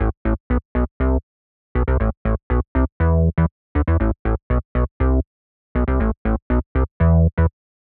23 Bass PT4.wav